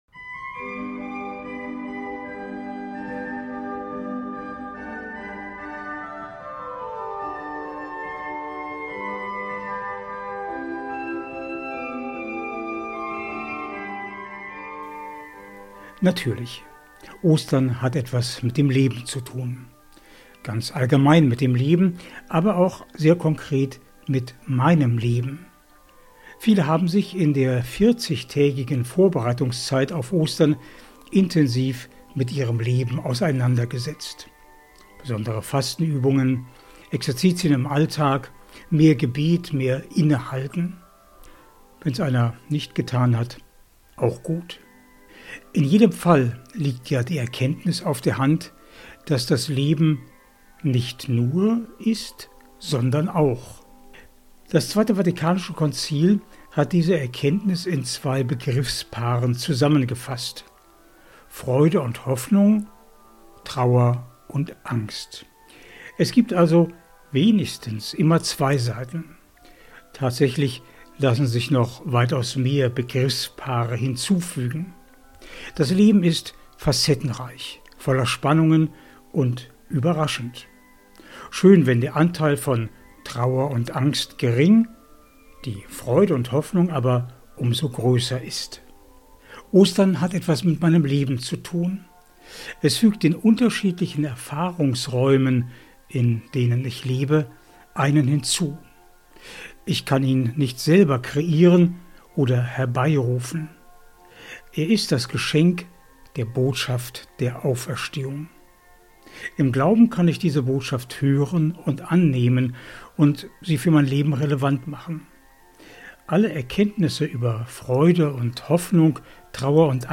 MEDITATION
Musik:privat